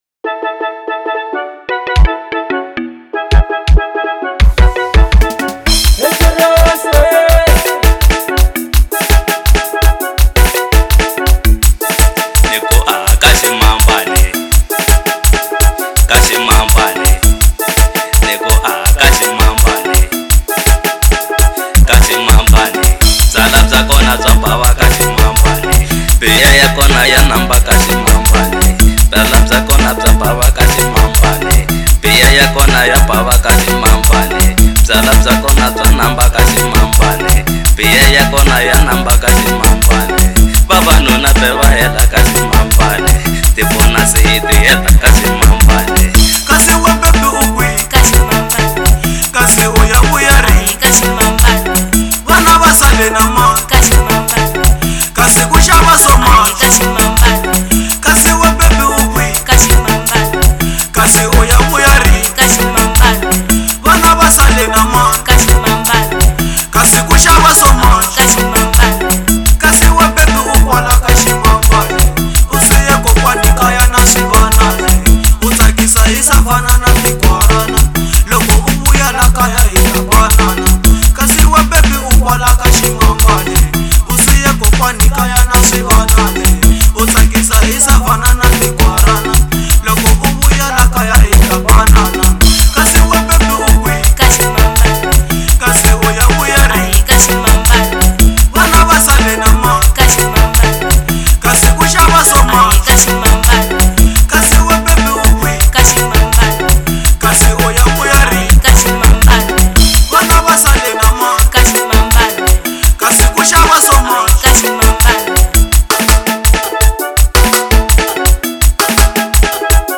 05:32 Genre : Xitsonga Size